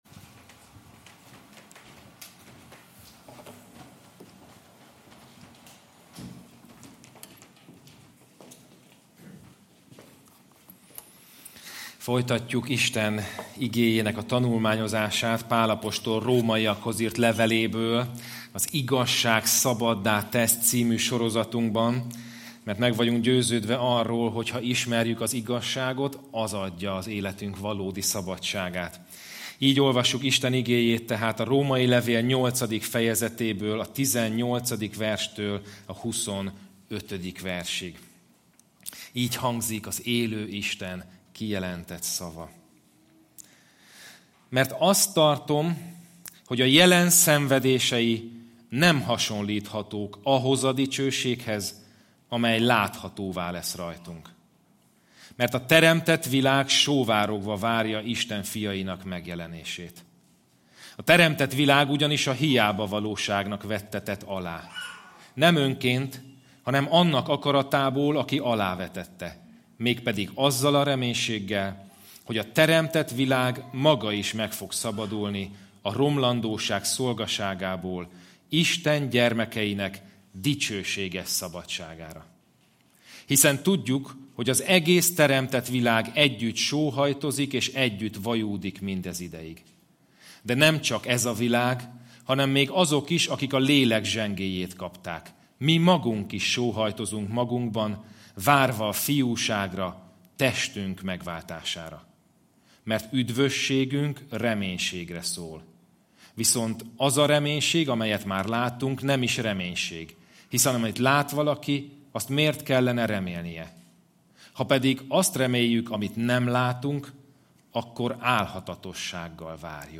Igehirdetések - Trinity Baptista Gyülekezet